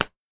menuhit.ogg